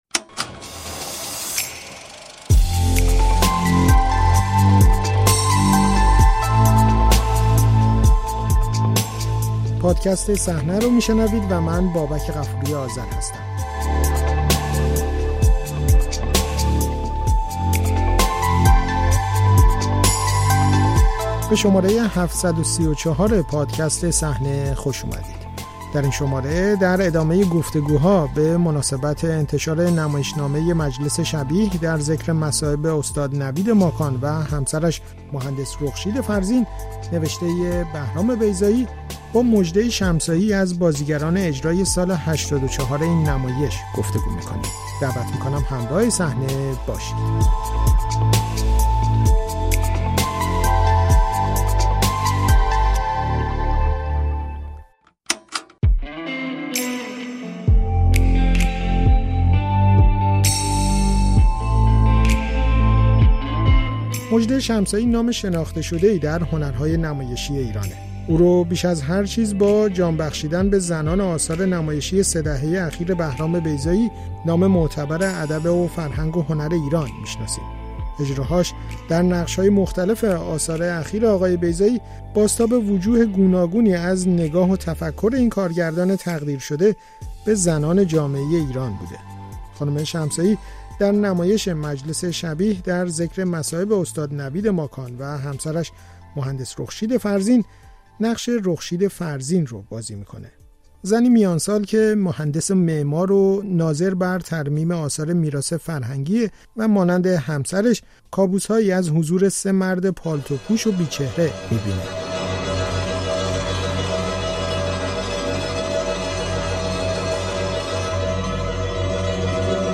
گفت‌وگو با مژده شمسایی؛ حقوق برابر زنان ایرانی با مردان در رنج